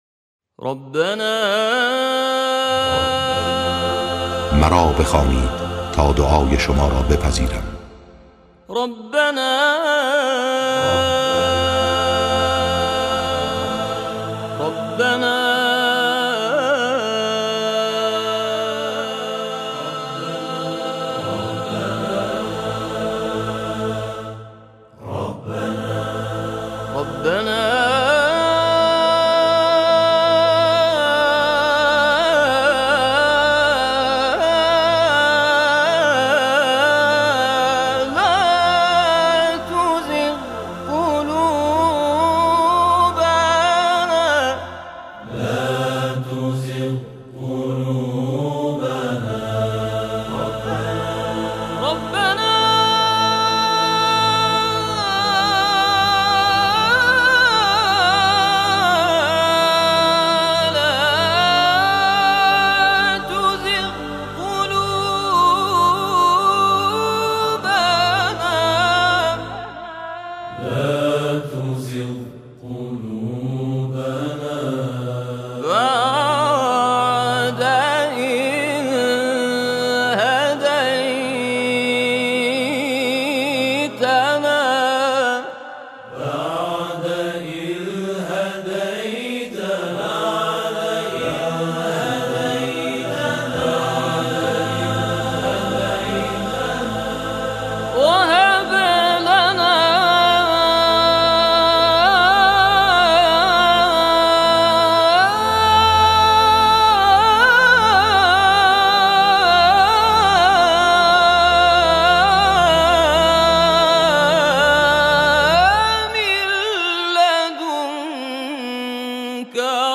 تواشیح